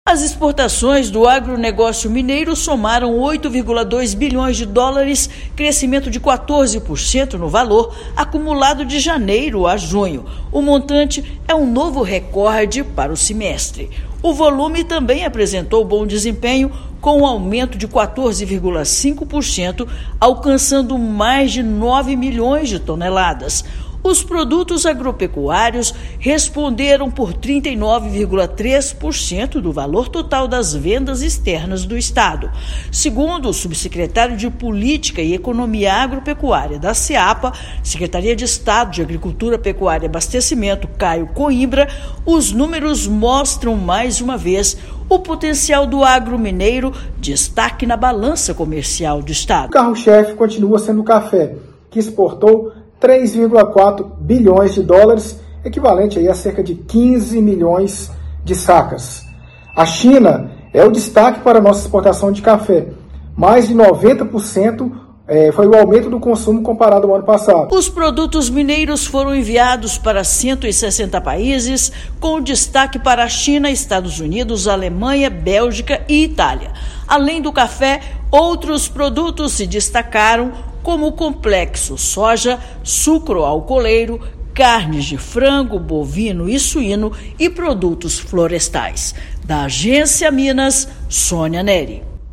Bom desempenho foi puxado pelo café, que representou 42% do valor total exportado. Os produtos do agro foram enviados para 160 países. Ouça matéria de rádio.